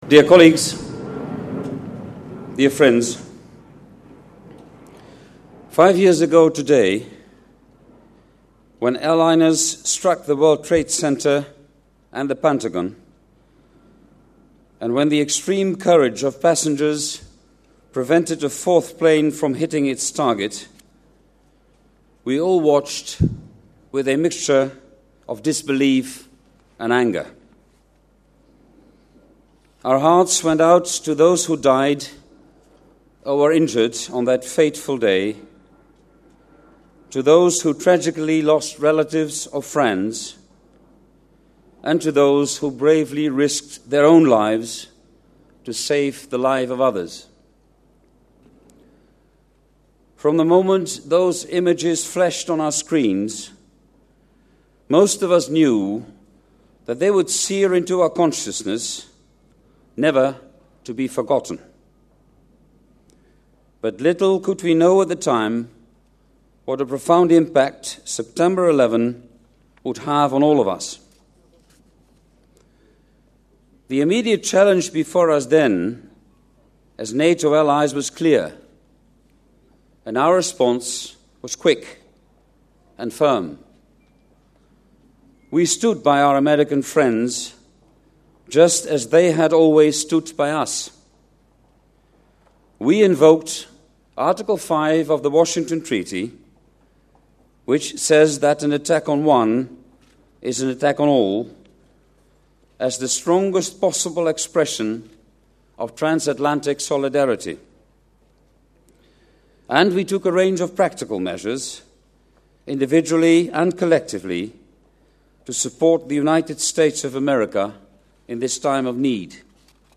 11 сентября 2006 г. в штаб-квартире НАТО состоялась церемония, посвященная памяти жертв террористических актов.
Audio Speech by NATO Secretary General, Jaap de Hoop Scheffer at the 9/11 Commemorative event at NATO HQ, opens new window